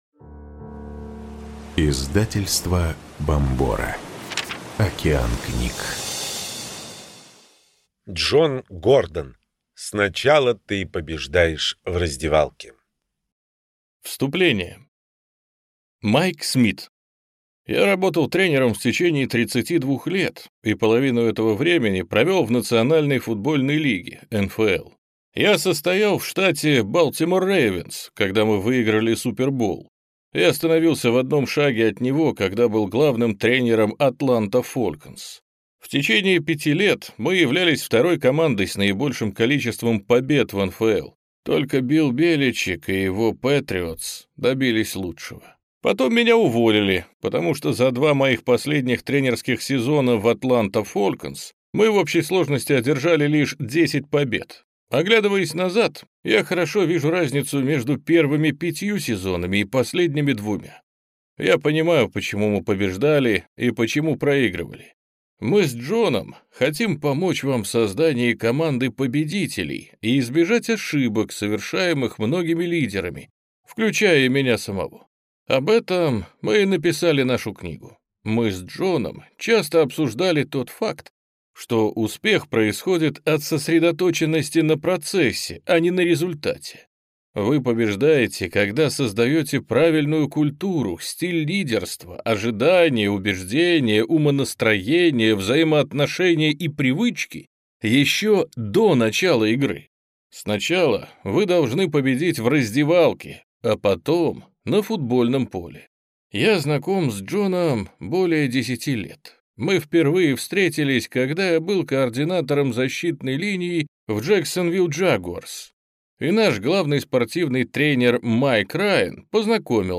Аудиокнига Сначала ты побеждаешь в раздевалке. 7 принципов успеха в бизнесе, спорте и жизни | Библиотека аудиокниг